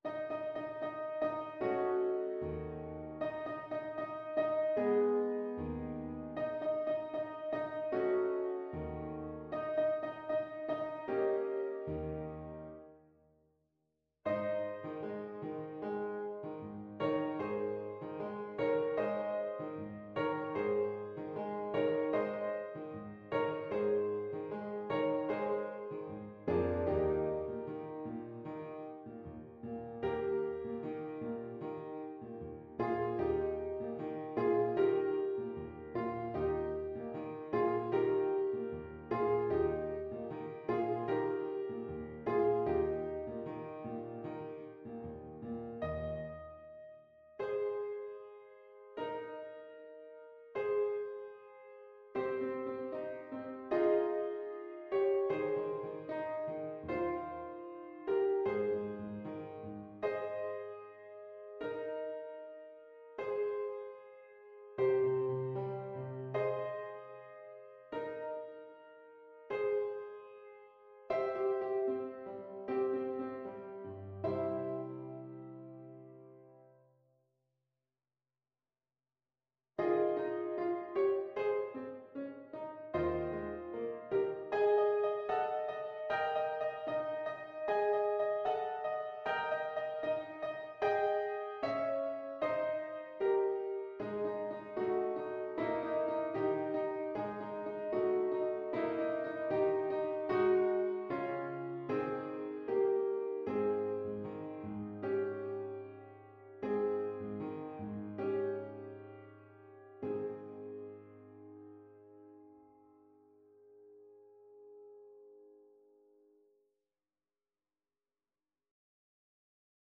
Play (or use space bar on your keyboard) Pause Music Playalong - Piano Accompaniment Playalong Band Accompaniment not yet available reset tempo print settings full screen
Ab major (Sounding Pitch) Eb major (French Horn in F) (View more Ab major Music for French Horn )
2/4 (View more 2/4 Music)
=76 Allegretto lusinghiero =104
Classical (View more Classical French Horn Music)